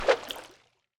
Water arrow sounds 1.wav